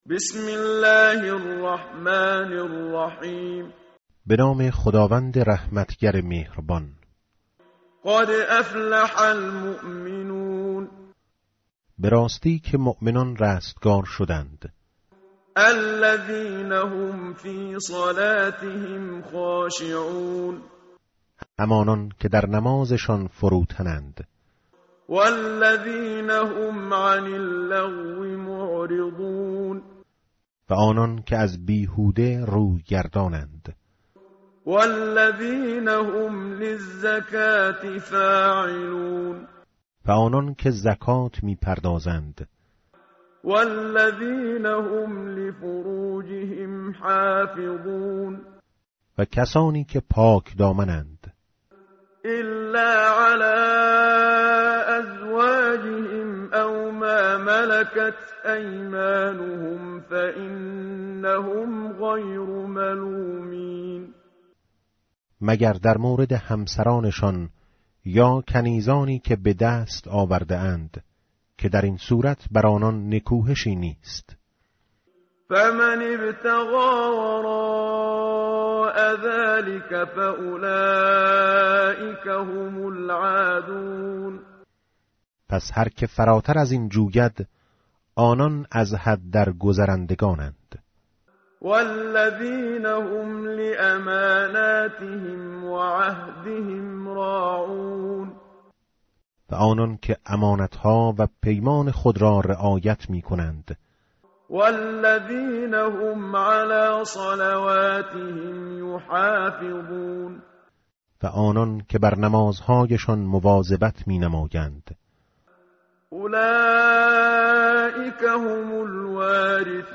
tartil_menshavi va tarjome_Page_342.mp3